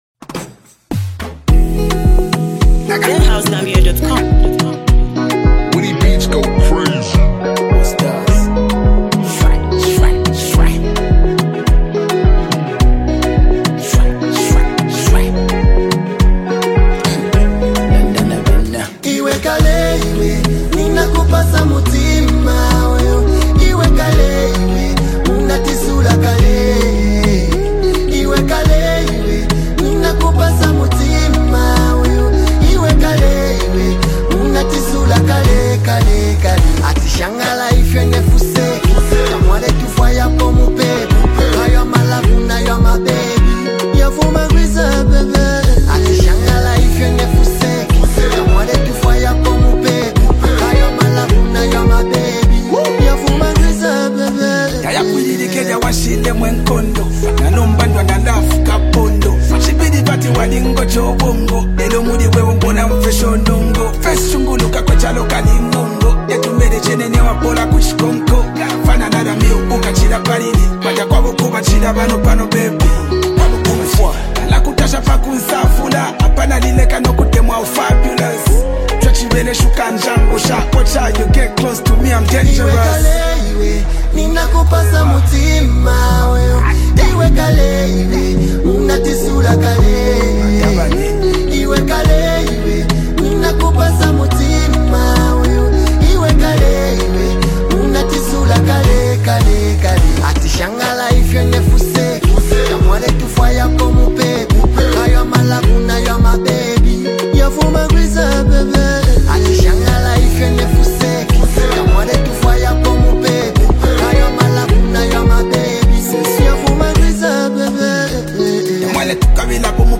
With sharp lyrics, raw emotion